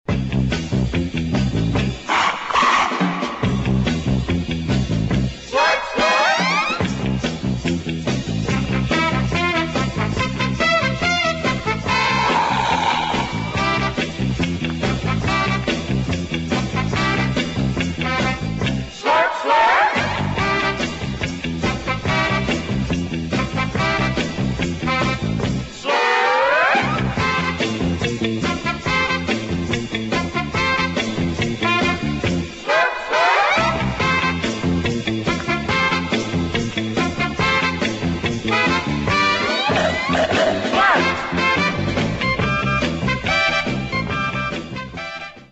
[ JAZZ / FUNK / DOWNBEAT ]